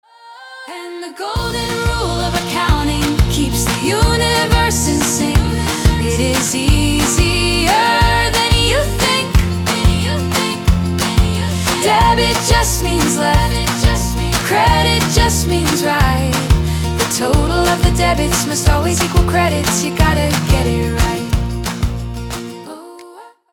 that teaches accounting concepts through two original songs.